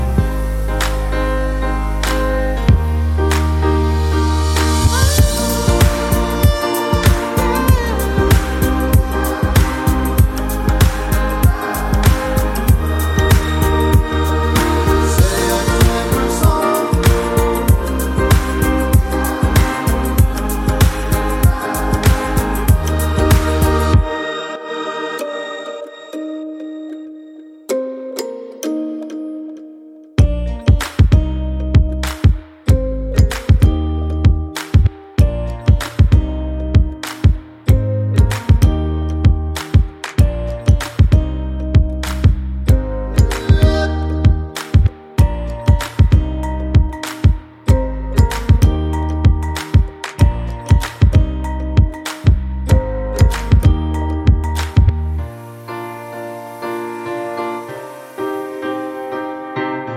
no Backing Vocals Pop (2010s) 3:33 Buy £1.50